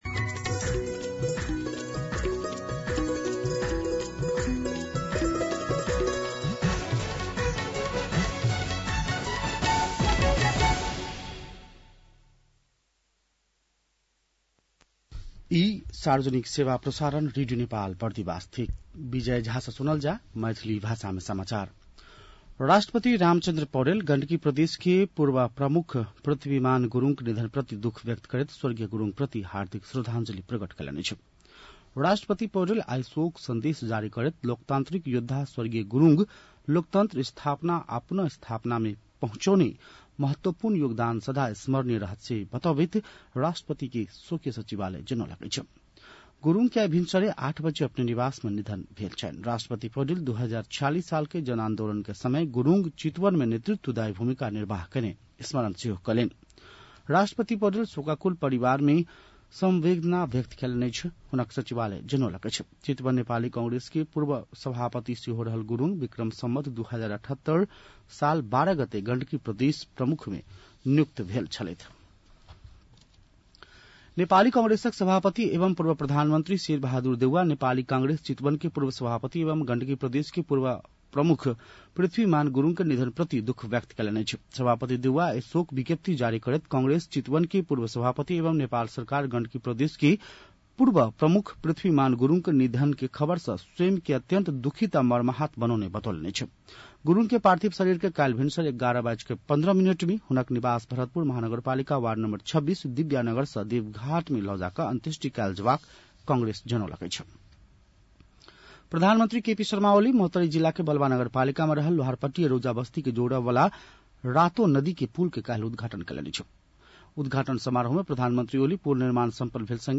मैथिली भाषामा समाचार : १० साउन , २०८२